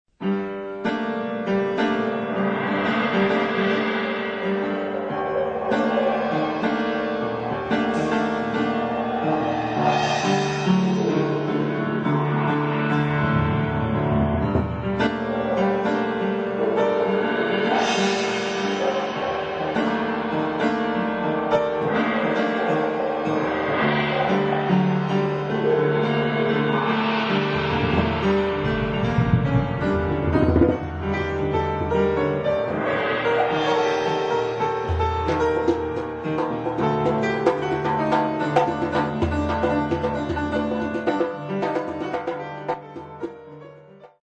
Piano
koto
Percussions